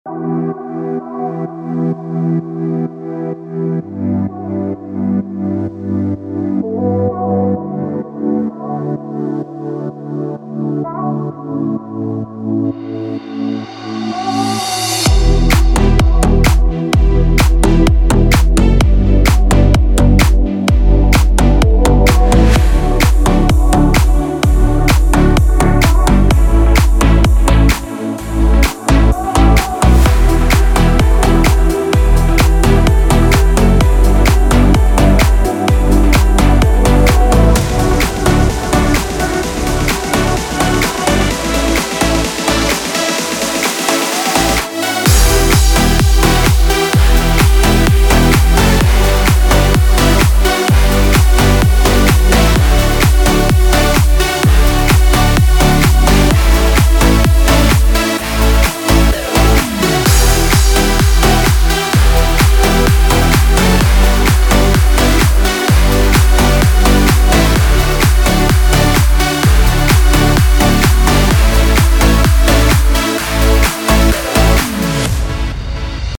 Popschlagersong im aktuellen Style.
Hier kannst du kurz ins Playback reinhören.
BPM – 128
Tonart – D-major